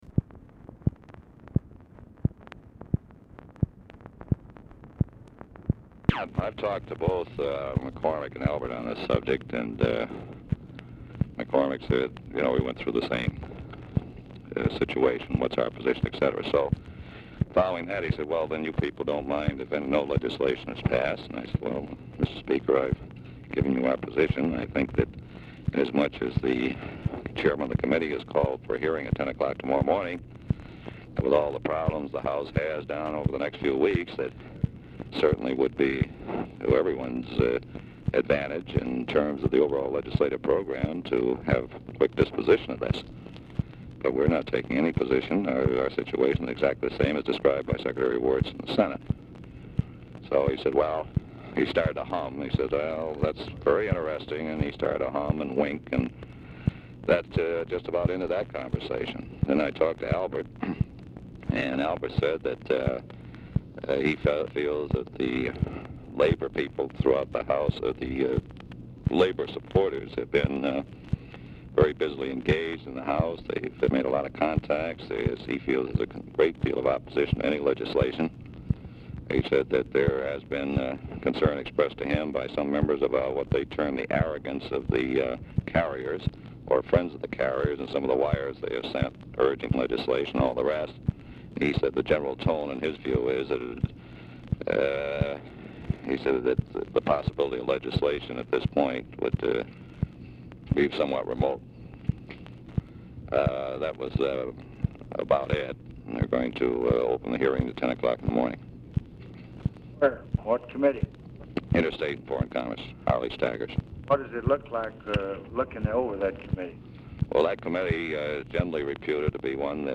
Telephone conversation # 10559, sound recording, LBJ and LARRY O'BRIEN, 8/4/1966, 6:55PM | Discover LBJ
RECORDING STARTS AFTER CONVERSATION HAS BEGUN
Format Dictation belt
Location Of Speaker 1 Oval Office or unknown location